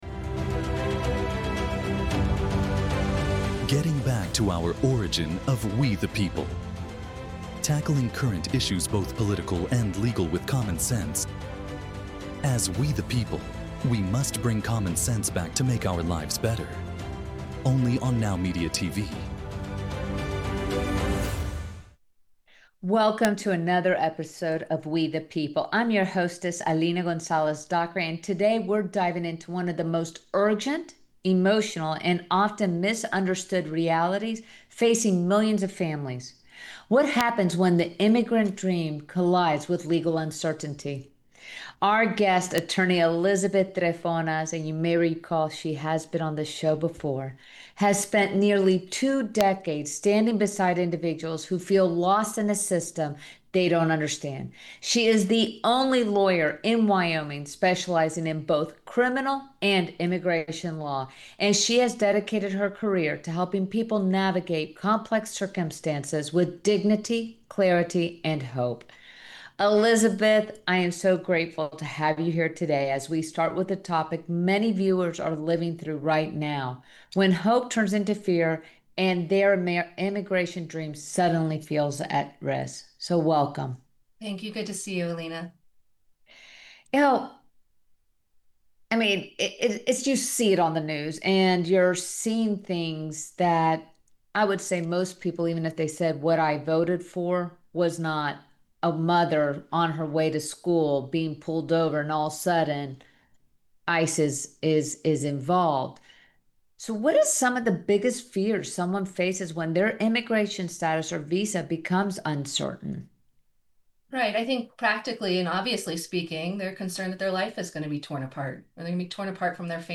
an in-depth conversation on immigration, criminal law, and due process in the United States.